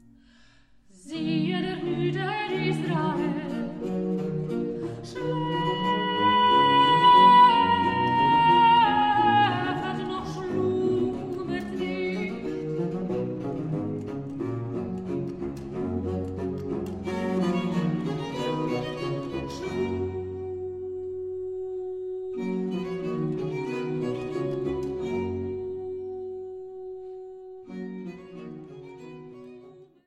Kirchweihkantaten
Sopran
Orgel und Leitung